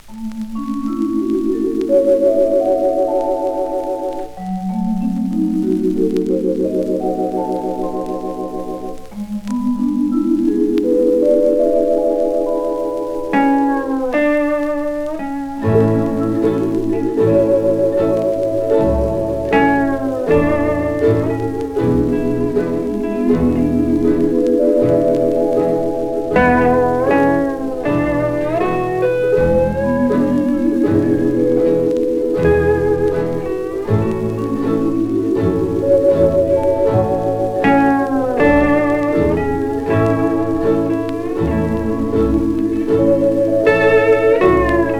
歌唱、楽曲、楽器とすべてがハワイ満点、魅惑的な良盤です。
World, Hawaii　USA　12inchレコード　33rpm　Mono